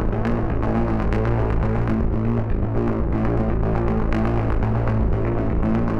Index of /musicradar/dystopian-drone-samples/Droney Arps/120bpm
DD_DroneyArp3_120-A.wav